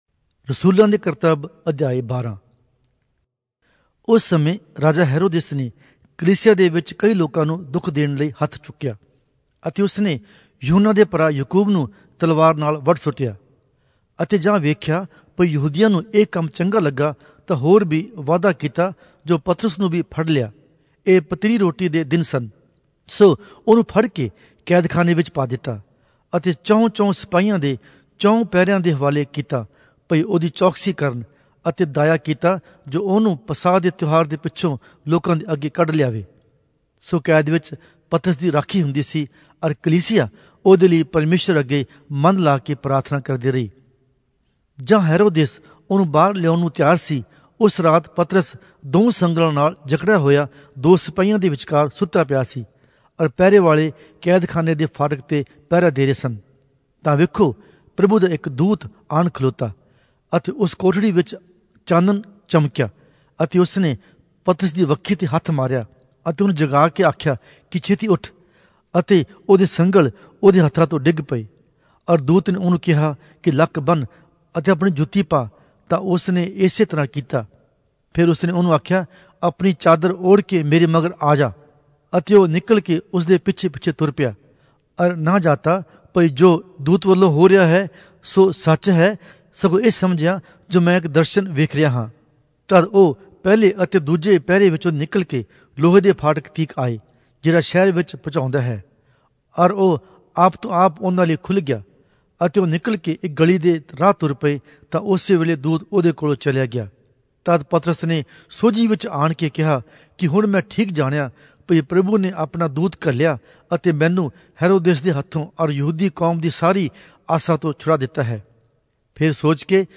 Punjabi Audio Bible - Acts 25 in Gnterp bible version